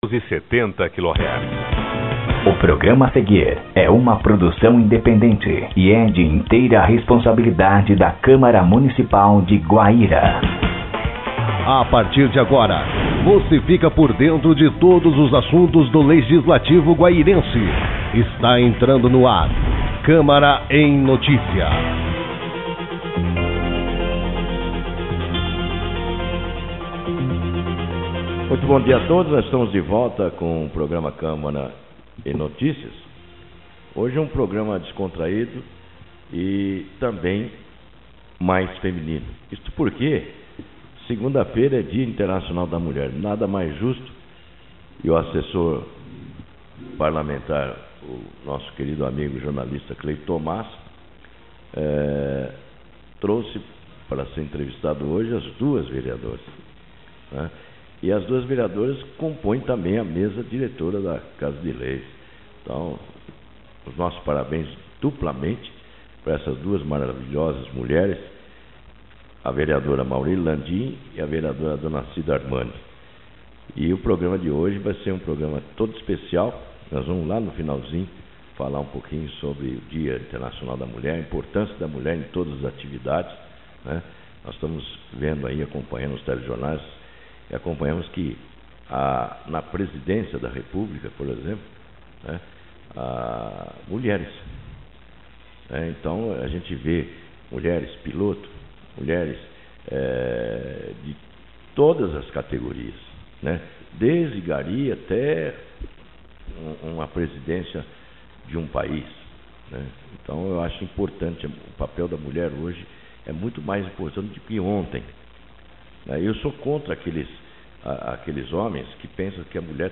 As vereadoras também falaram sobre a importância da mulher na sociedade. Confira a entrevista: